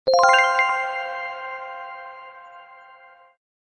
Hiệu ứng âm thanh Hoàn Thành
Thể loại: Hiệu ứng âm thanh
Description: Hiệu ứng âm thanh Hoàn Thành là một loại âm thanh ngắn, thường được sử dụng trong biên tập video để đánh dấu sự kết thúc của một quá trình, nhiệm vụ hoặc hành động, trả lời đúng, chính xác, cộng điểm là hiệu ứng âm thanh vui nhộn, tạo cảm giác hài lòng và hoàn thiện...
am-thanh-hoan-thanh-www_tiengdong_com.mp3